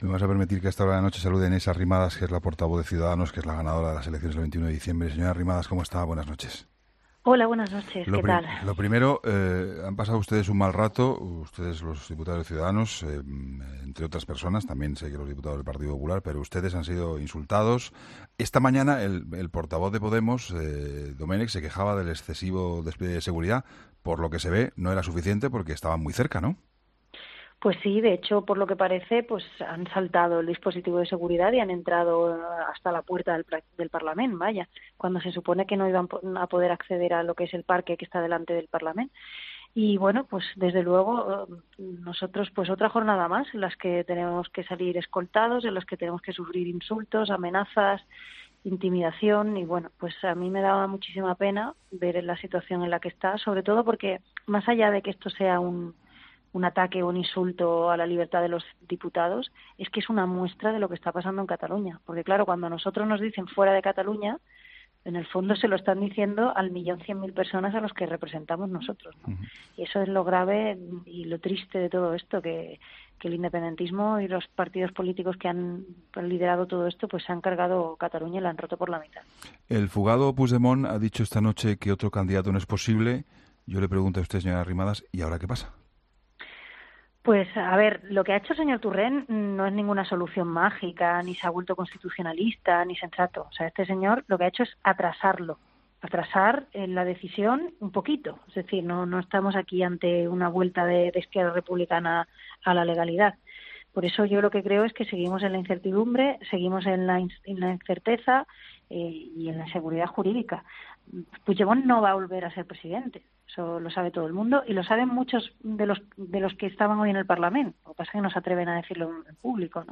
Entrevistas en La Linterna
Inés Arrimadas ha sido entrevistada en el tiempo de la tertulia de 'La Linterna', con Juan Pablo Colmenarejo, tras la jornada de este martes en el Parlamento autonómico catalán